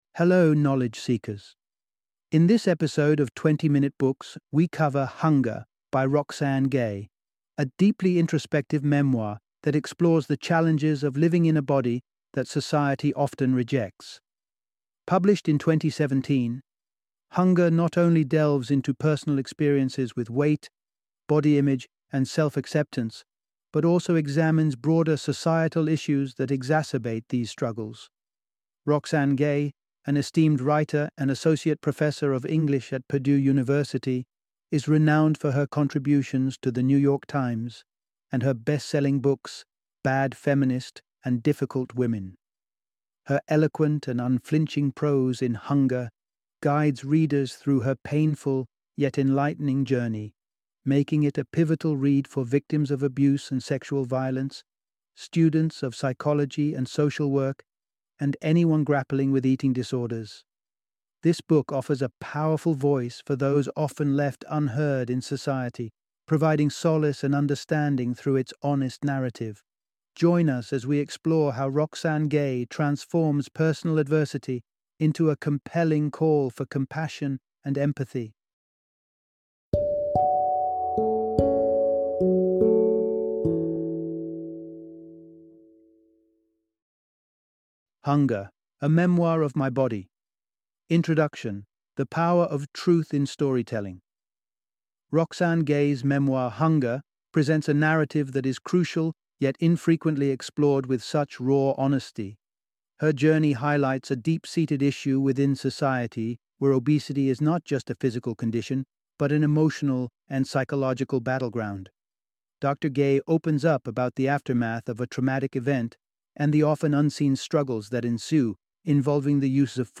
Hunger - Audiobook Summary